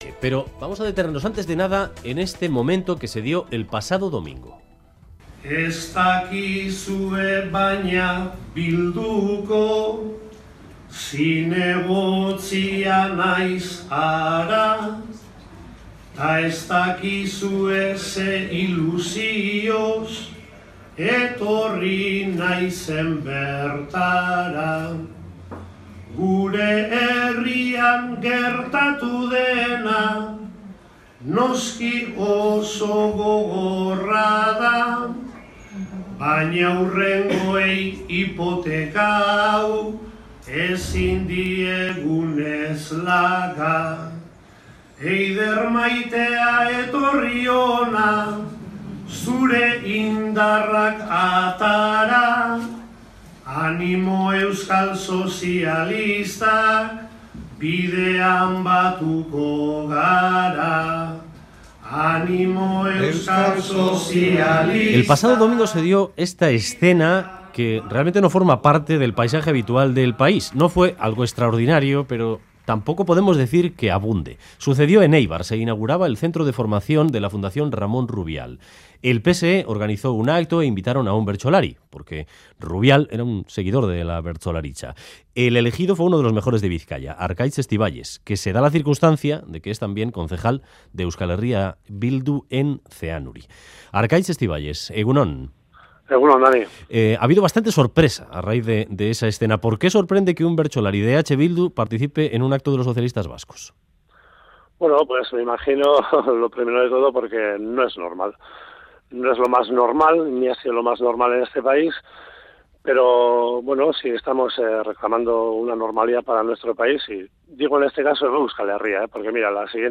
Audio: Entrevista en 'Boulevard' a Arkaitz Estiballes, bertsolari y concejal de EH Bildu en Zeanuri, tras participar en un acto organizado por el PSE en Eibar.